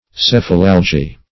Search Result for " cephalalgy" : The Collaborative International Dictionary of English v.0.48: Cephalalgia \Ceph`a*lal"gi*a\, Cephalalgy \Ceph"a*lal`gy\, n. [L. cephalalgia, Gr.
cephalalgy.mp3